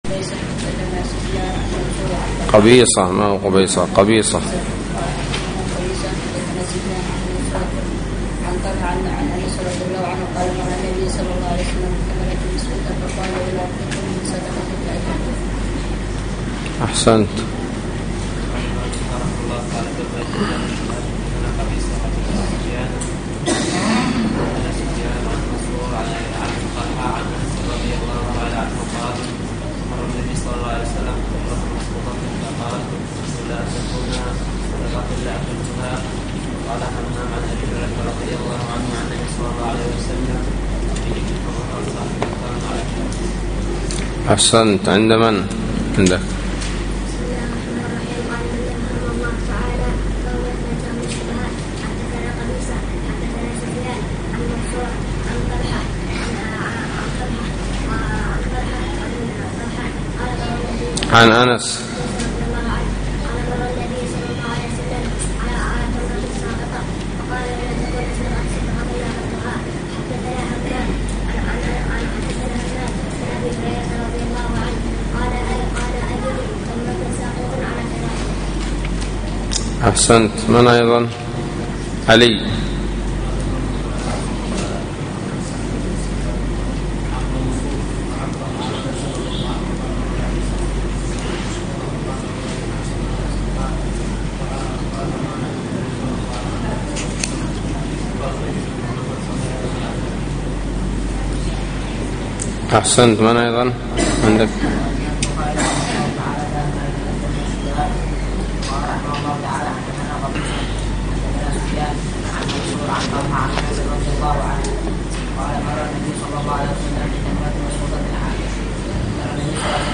الدرس السادس من كتاب البيوع من صحيح الإمام البخاري